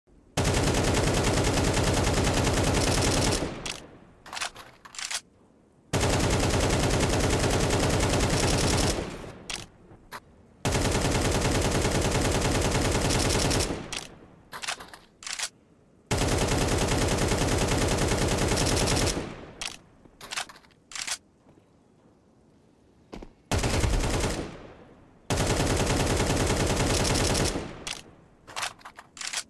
AK 47 sound before & after sound effects free download
AK-47 sound before & after the new CS2 update